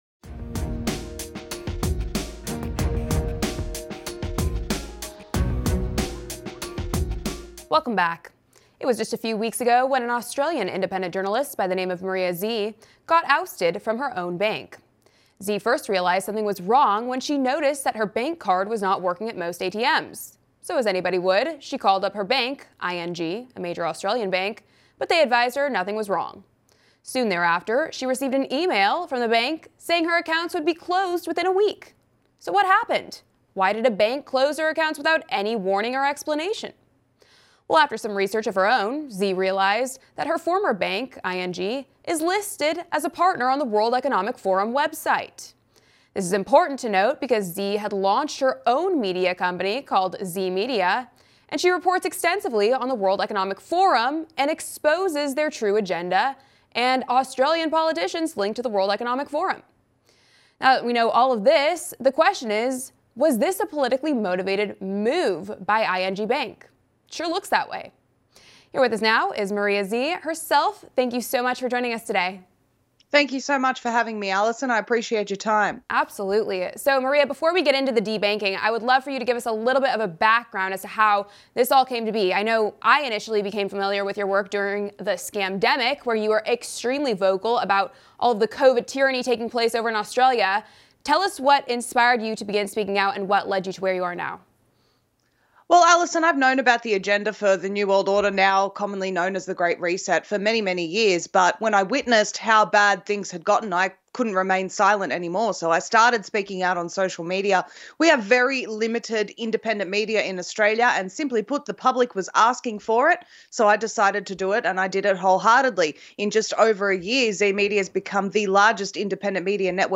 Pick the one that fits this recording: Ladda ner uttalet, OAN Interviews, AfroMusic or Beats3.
OAN Interviews